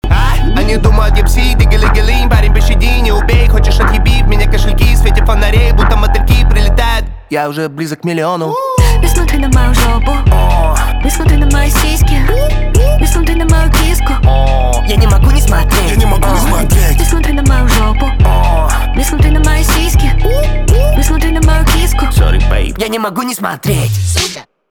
русский рэп
битовые , басы , качающие